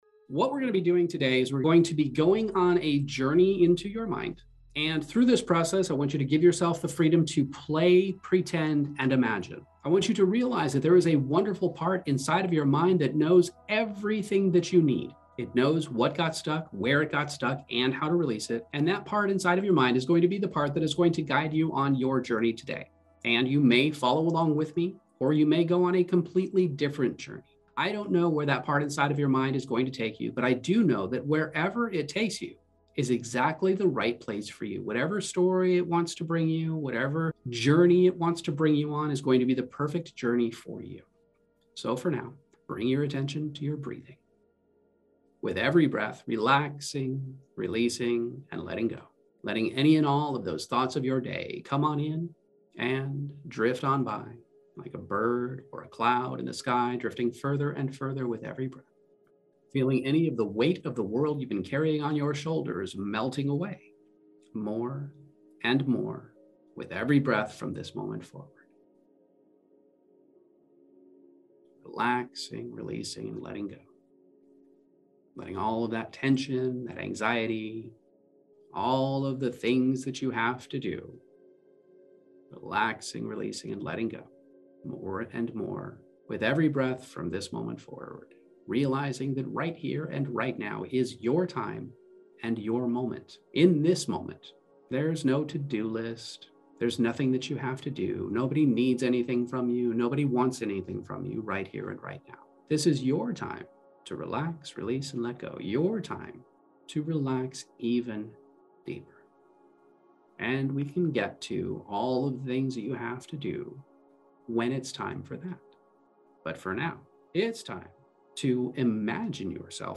This guided hypnosis meditation is like hitting the reset button on your brain. It starts with you chilling out and getting comfy, then diving into the fun stuff—imagining, playing, and pretending.